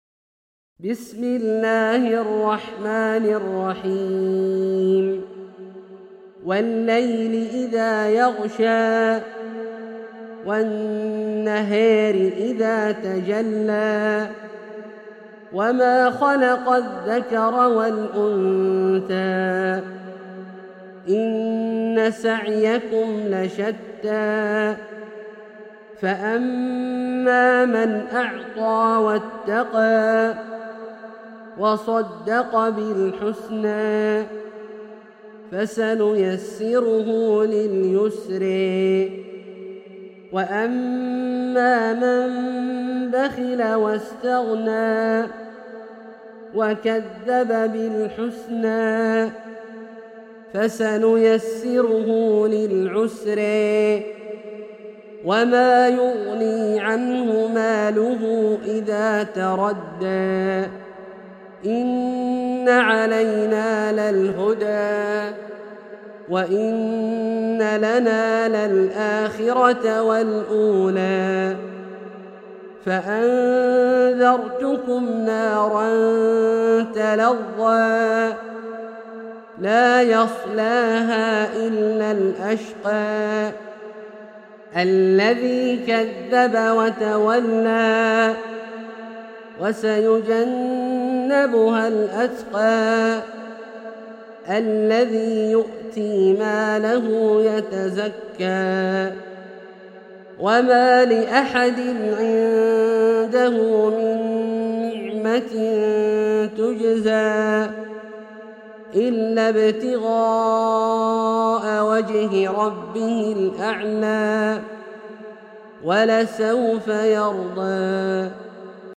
سورة الليل - برواية الدوري عن أبي عمرو البصري > مصحف برواية الدوري عن أبي عمرو البصري > المصحف - تلاوات عبدالله الجهني